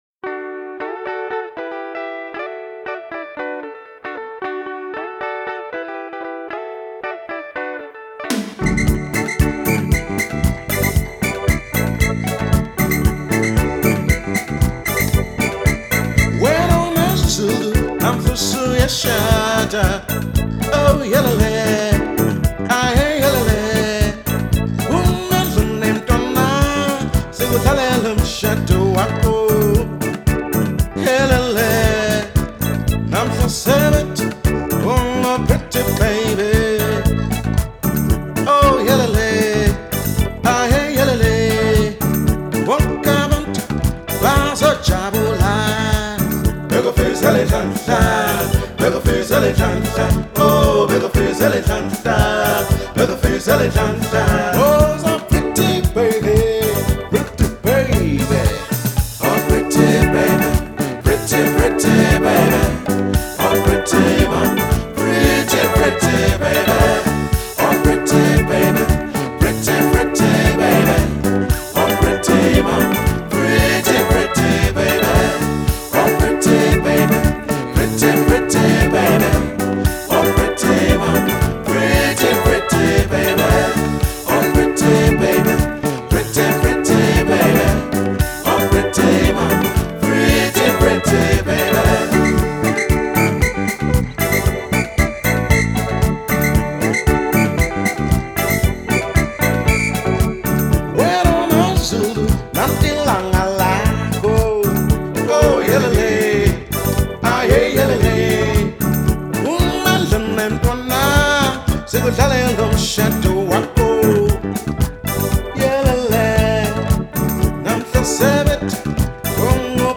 up tempo and exuberant music